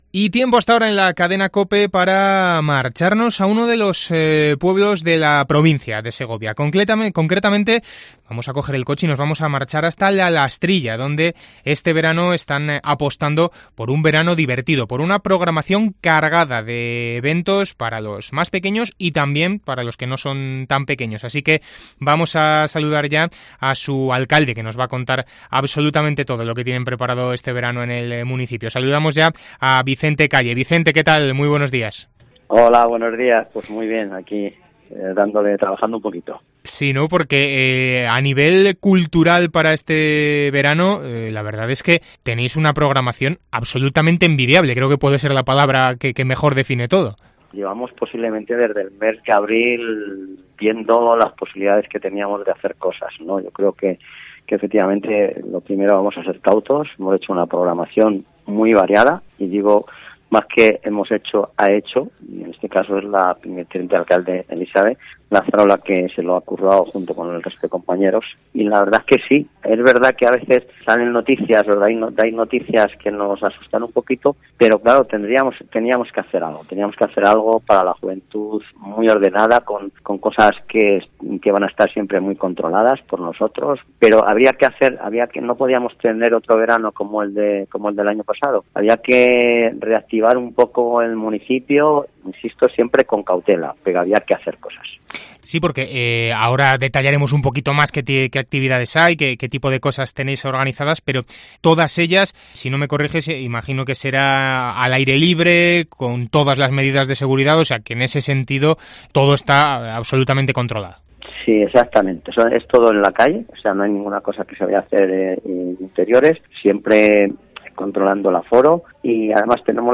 El alcalde de La Lastrilla, Vicente Calle, presenta en COPE Segovia el programa cultural y variado que los segovianos van a poder disfrutar en las fiestas de verano de La Lastrilla